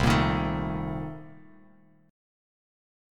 Cm13 Chord
Listen to Cm13 strummed